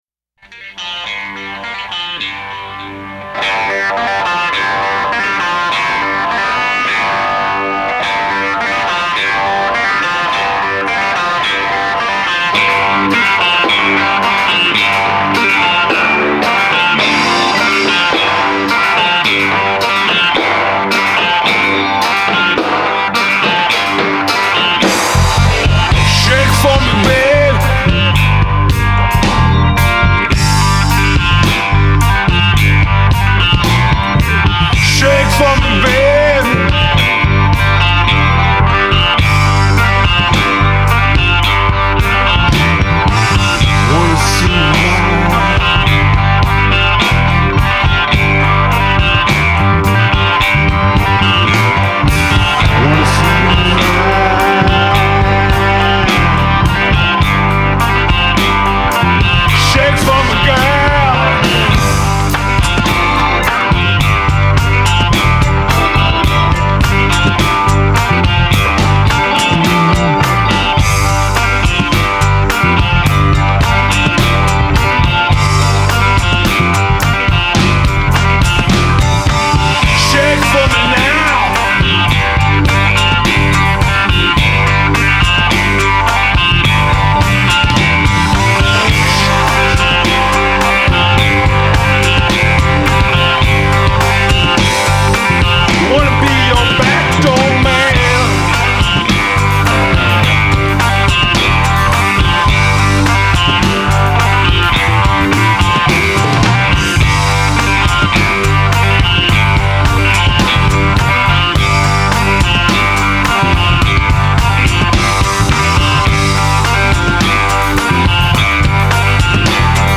Жанр: Blues-Rock